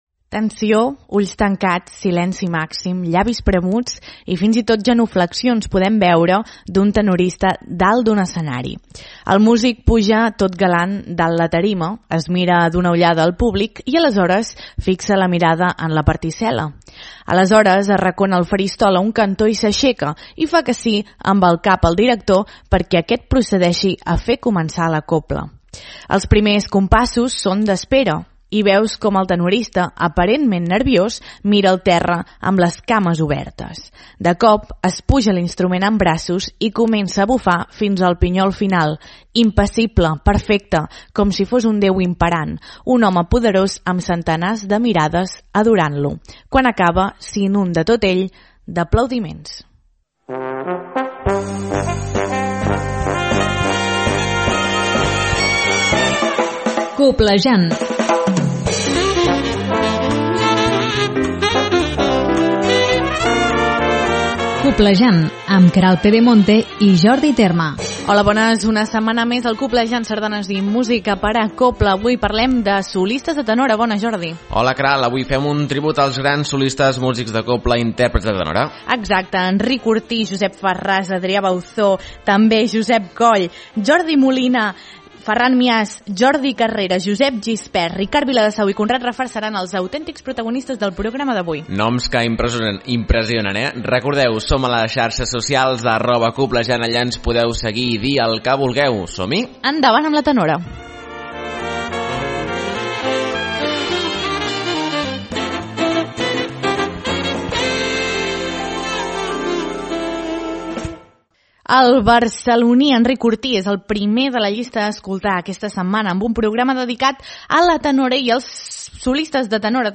Parlarem de la biografia professional dels tenores i escoltarem el seu so.